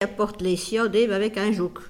Elle provient de Saint-Hilaire-des-Loges.
Locution ( parler, expression, langue,... )